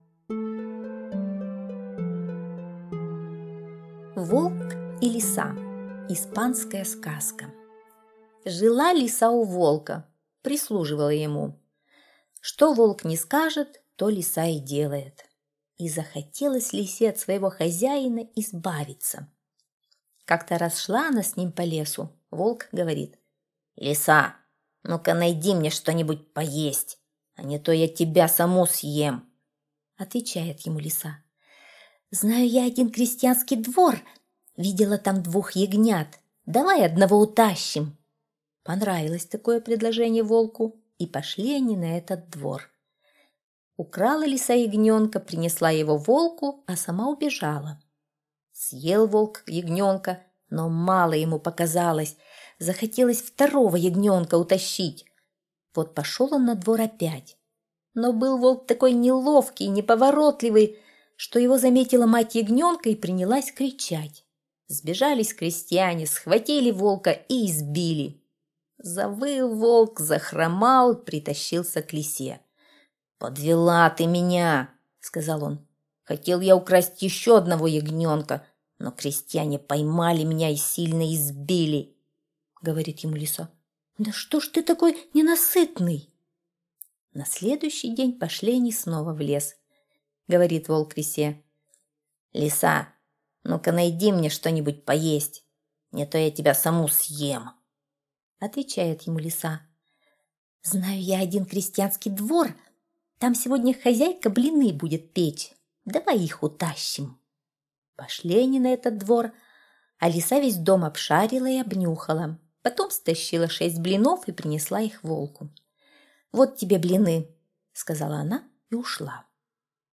Волк и лиса - испанская аудиосказка - слушать онлайн